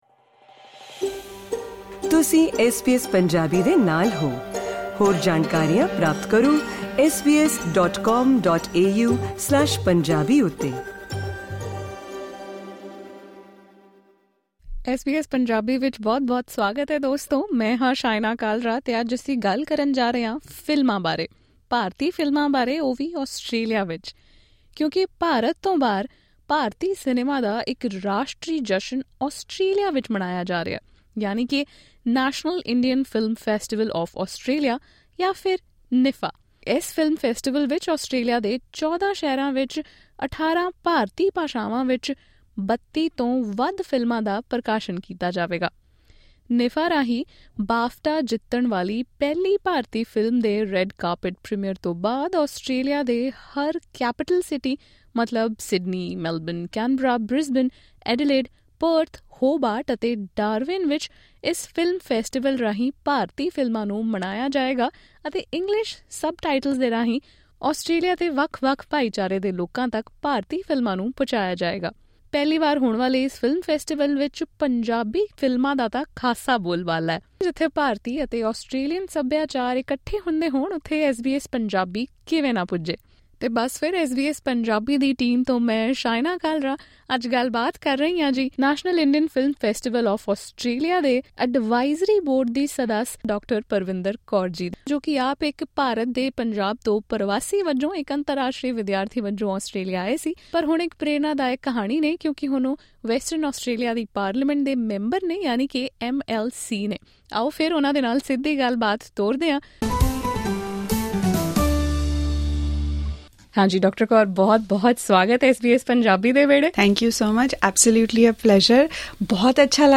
Speaking to SBS Punjabi, Indian-origin Member of the Legislative Council of Western Australia, Dr Parvinder Kaur, said the festival is a great opportunity to connect Australian audiences with the Indian film industry, its artists, and its rich linguistic diversity. She also noted that the festival could highlight Australia as an attractive destination for filming.